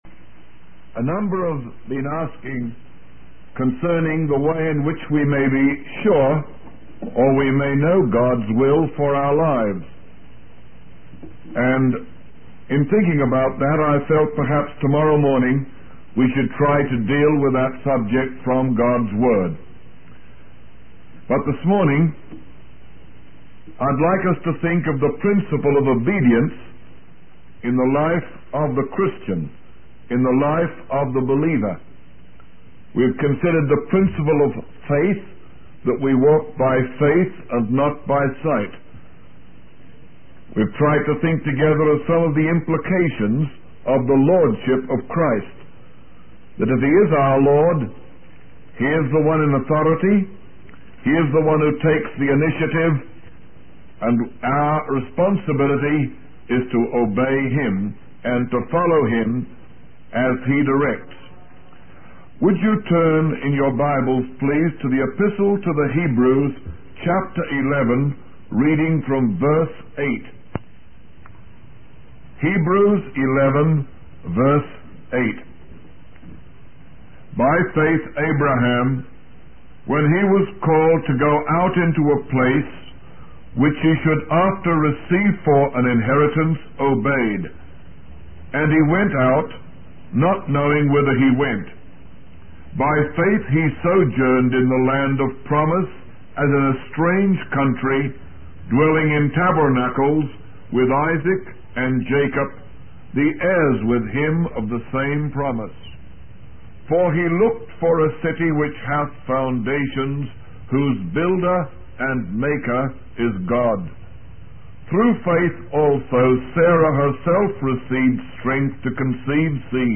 The sermon concludes with a call to trust and obey God in all aspects of life, including relationships and vocations.